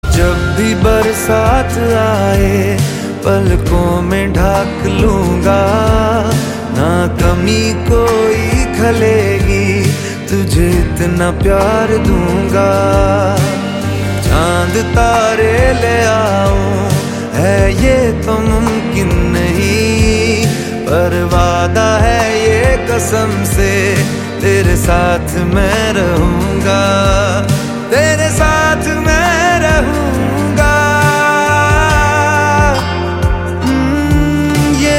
soulful and romantic tune